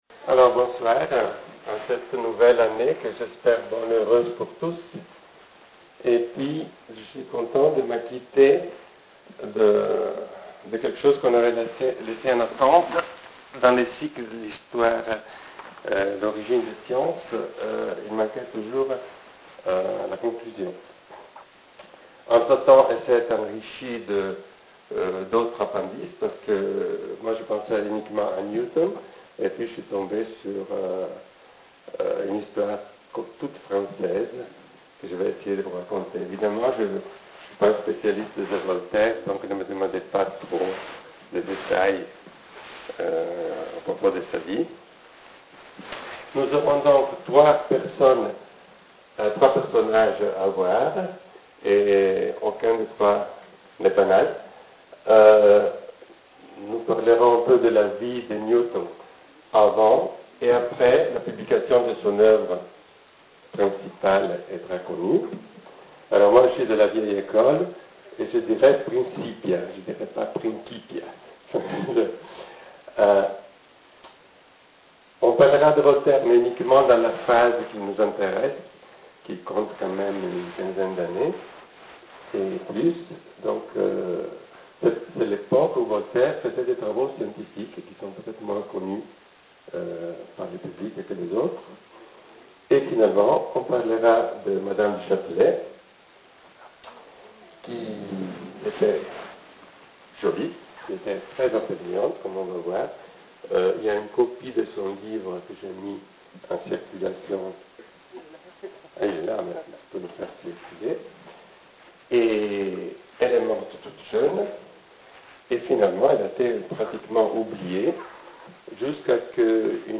Conférence tenue à Cassis le 30.01.2007 :